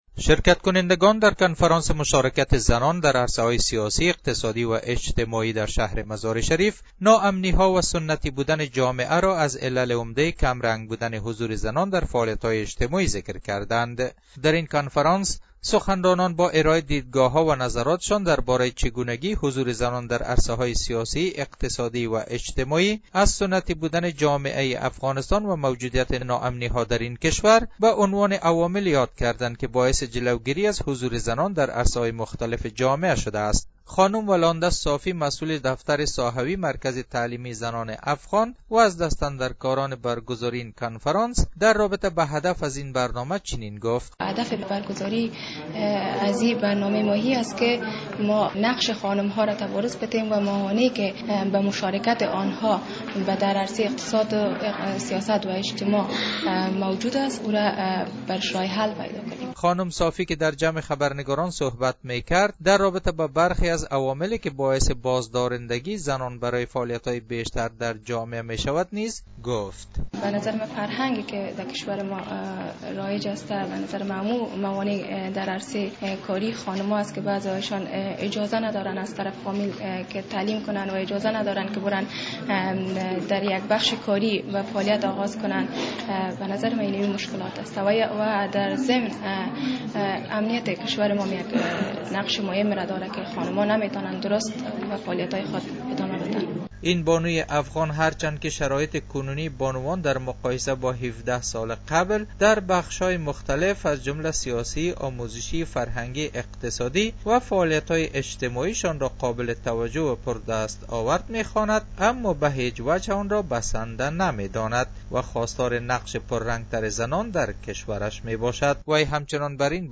گزارش : برگزاری کنفرانس مشارکت زنان در عرصه های اجتماعی در مزار شریف